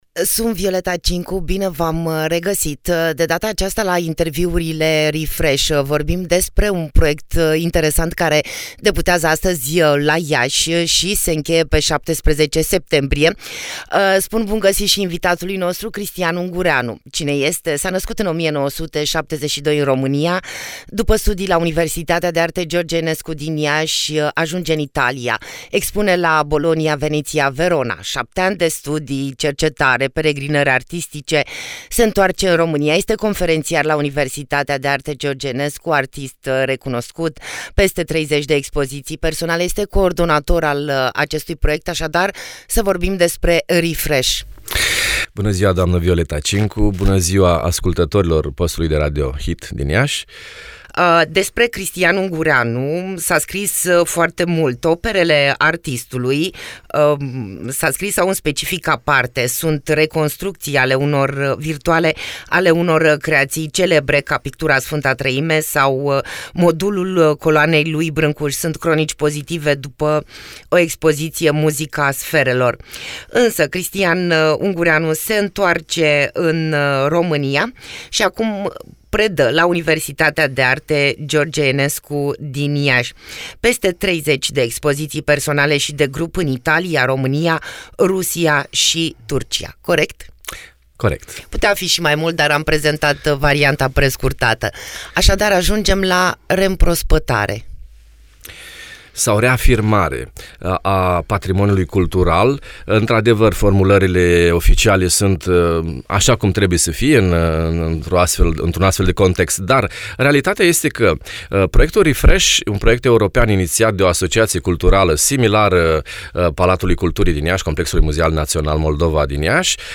Refresh_12sept18_Interviu-1.mp3